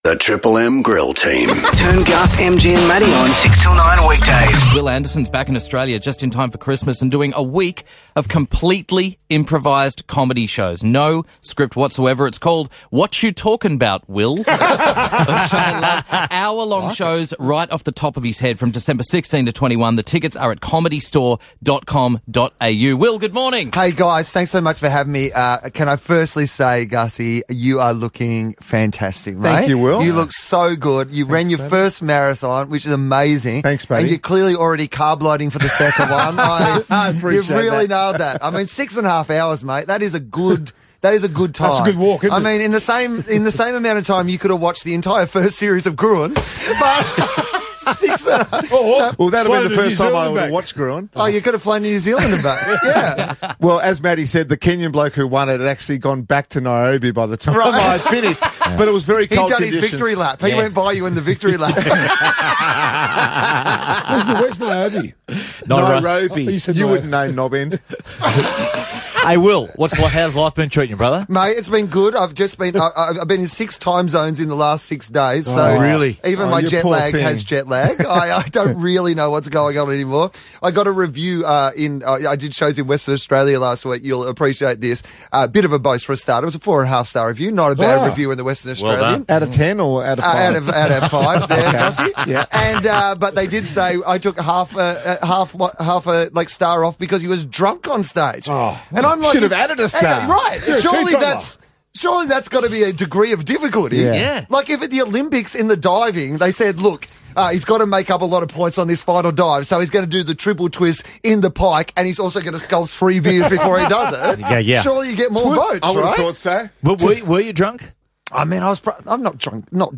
Comedian Wil Anderson visited the Grill Team to talk about his upcoming Comedy Store appearance: Whatchu Talkin' 'Bout, Wil?